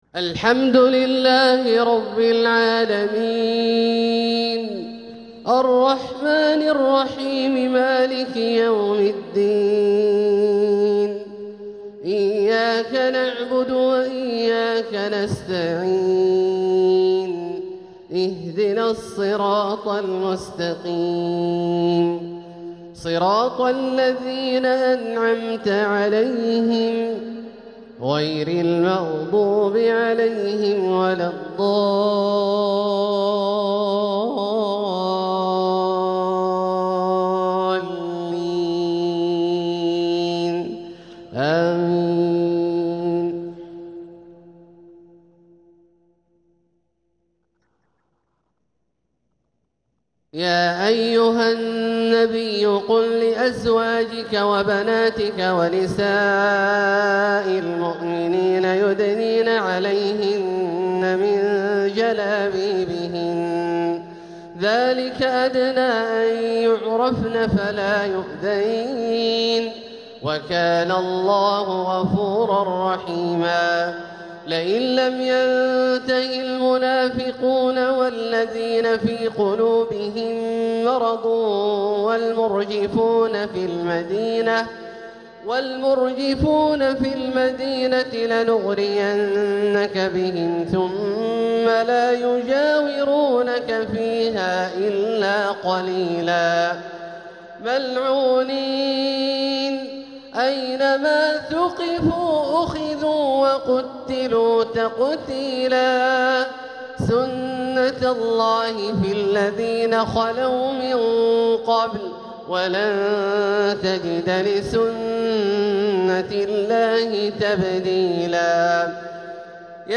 ما أجمله من ترتيل وما أعذبه من تحبير! تلاوة بهية لخواتيم سورة الأحزاب | عشاء الإثنين ٢١ ربيع الآخر ١٤٤٧ هـ > ١٤٤٧هـ > الفروض - تلاوات عبدالله الجهني